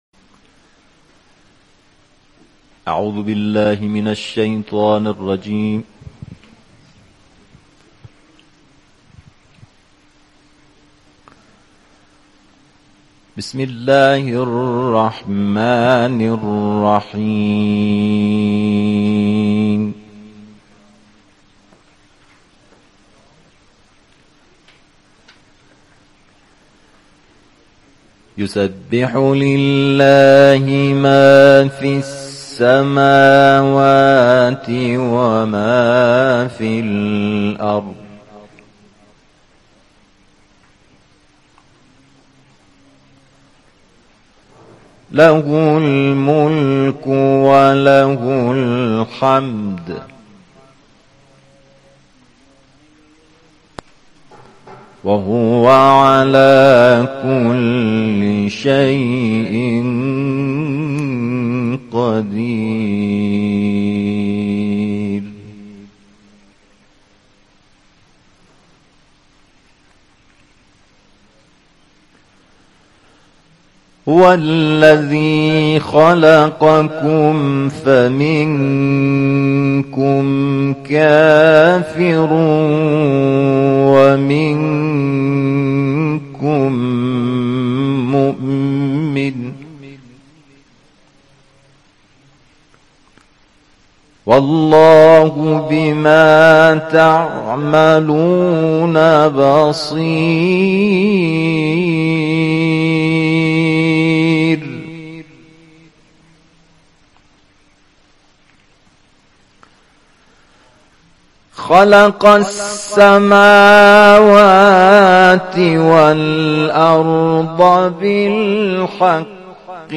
جدیدترین تلاوت